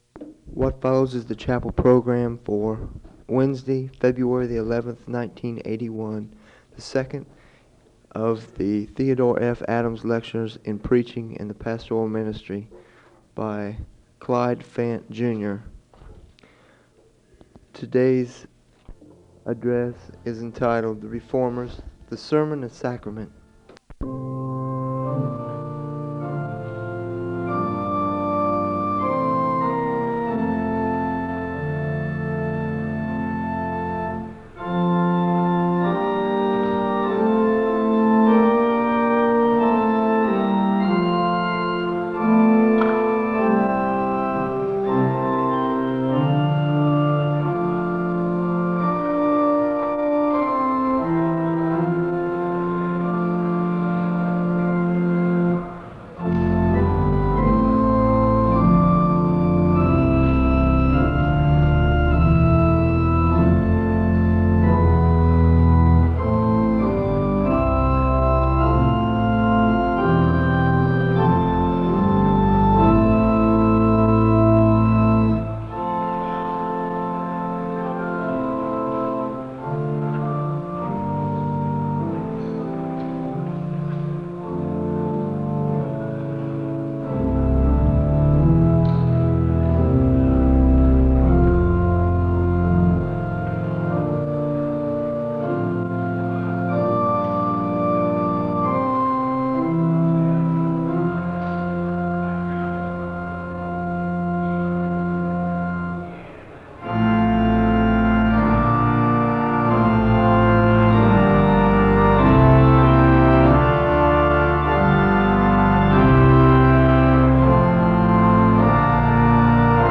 The service begins with organ music (00:00-04:41).
The choir sings a song of worship (08:00-11:41).
The service ends with a benediction (55:57-56:23).
SEBTS Chapel and Special Event Recordings